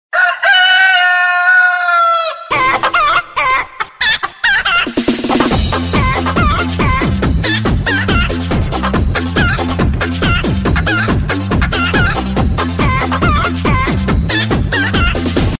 Kategori Dyr